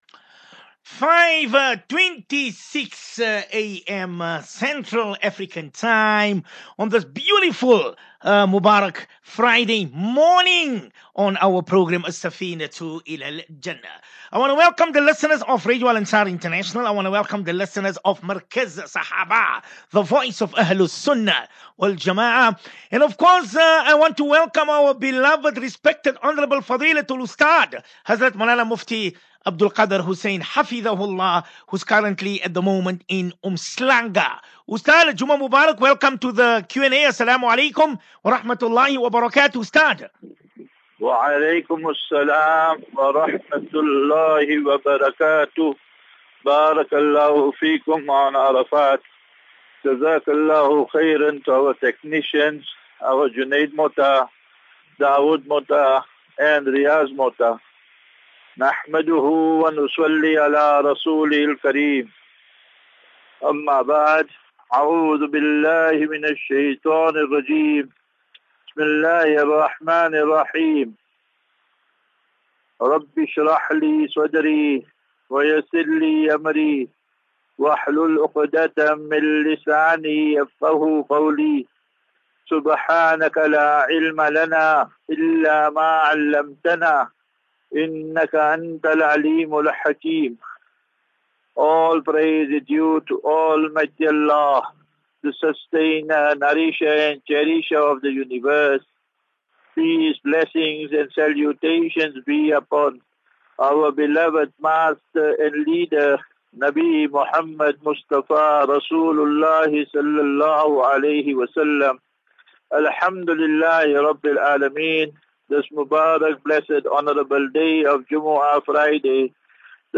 As Safinatu Ilal Jannah Naseeha and Q and A 12 May 12 May 23 Assafinatu